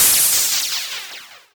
fall.wav